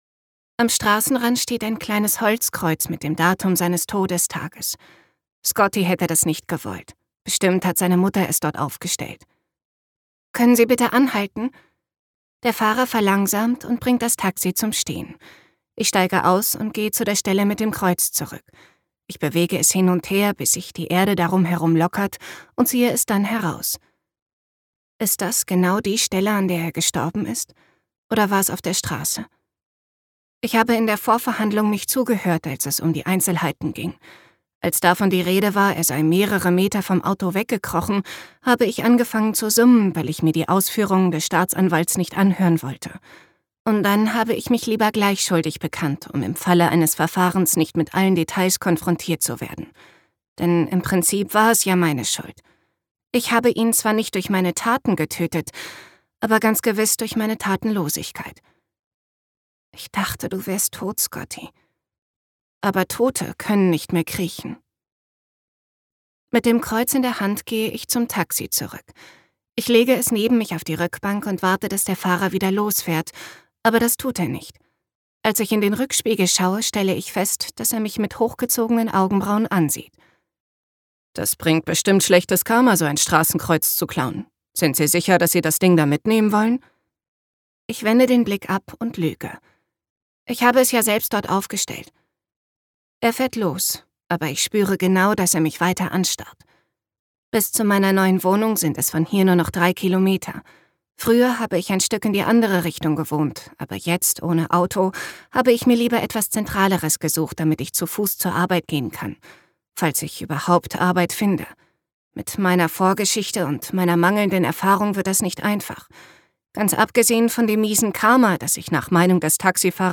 Für immer ein Teil von dir (DE) audiokniha
Ukázka z knihy